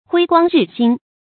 辉光日新 huī guāng rì xīn
辉光日新发音
成语注音 ㄏㄨㄟ ㄍㄨㄤ ㄖㄧˋ ㄒㄧㄣ